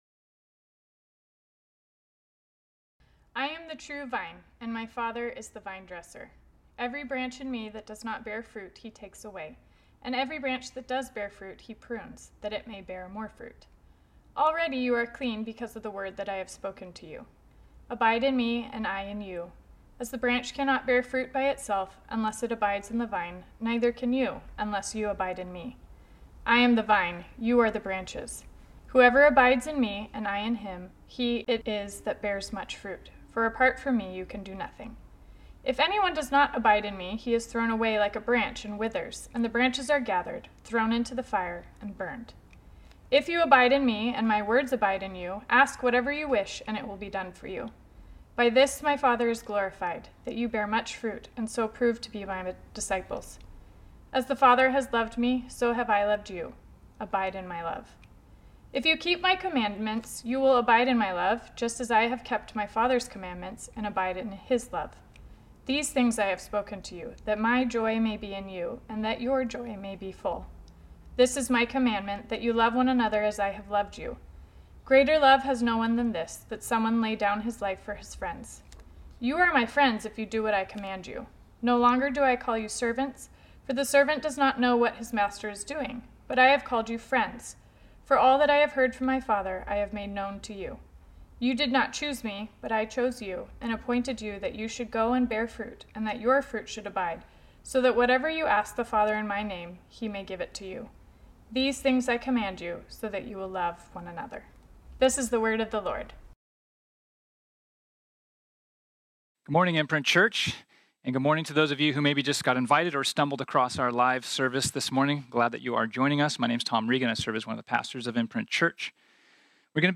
This sermon was originally preached on Sunday, May 31, 2020.